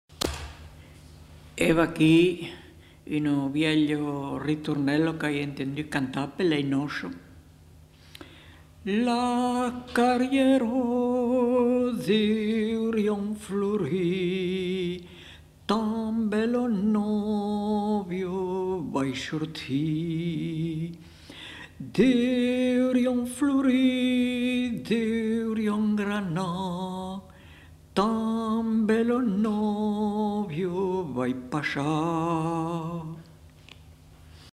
Aire culturelle : Périgord
Genre : chant
Effectif : 1
Type de voix : voix d'homme